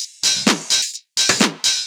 Index of /VEE/VEE Electro Loops 128 BPM
VEE Electro Loop 404.wav